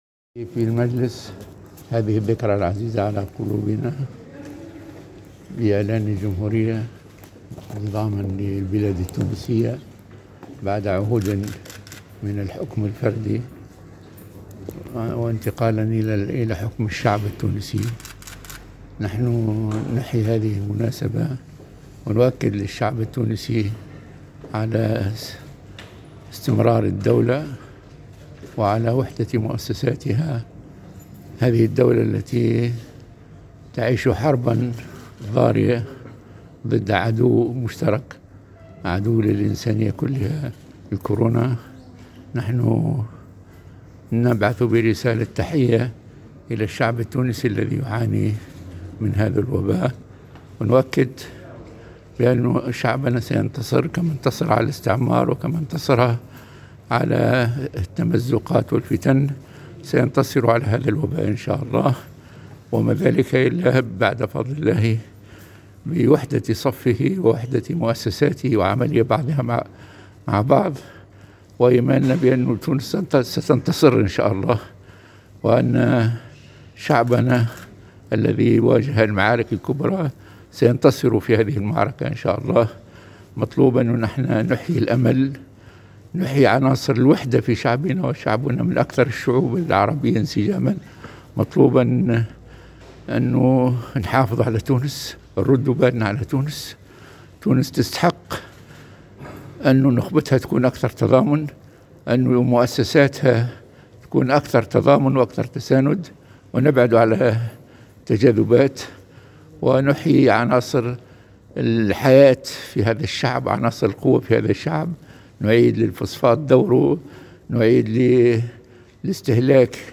أشرف اليوم الأحد 25 جويلية 2021 ، رئيس البرلمان راشد الغنوشي ، على موكب الإحتفال بعيد الجمهورية بالبرلمان ، وهو الظهور الأول له بعد اصابته بفيروس كورونا.